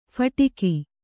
♪ phaṭiki